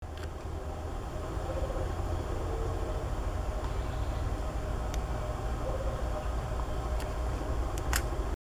Rufous-capped Motmot (Baryphthengus ruficapillus)
Life Stage: Adult
Location or protected area: Parque Nacional Iguazú
Condition: Wild
Certainty: Recorded vocal